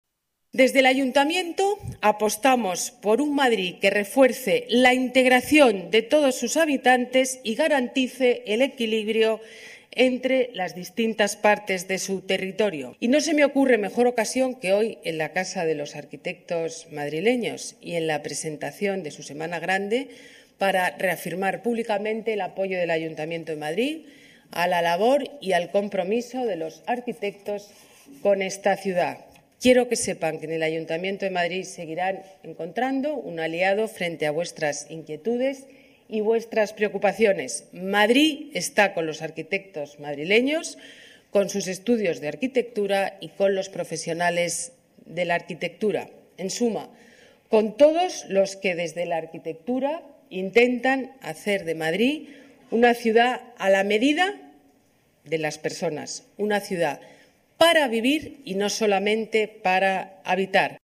Nueva ventana:Declaraciones de la alcaldesa, Ana Botella: Semana de la Arquitectura. Madrid, ciudad para vivir